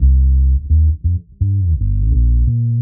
Index of /musicradar/dub-designer-samples/85bpm/Bass
DD_PBass_85_C.wav